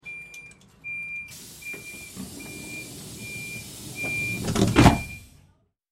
Звуки дверей поезда
Звук закрывающихся дверей электропоезда с писком